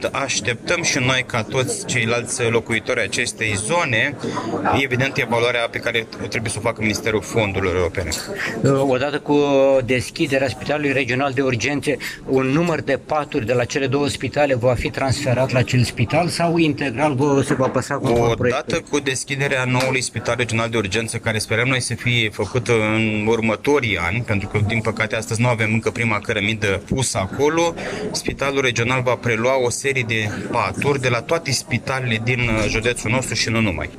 Pentru acest obiectiv, Ministerul Sănătății a emis avizul de oportunitate, iar Consiliul Județean a acordat autorizația de construire, după cum a precizat președintele Consiliului Județean Iași, Costel Alexe: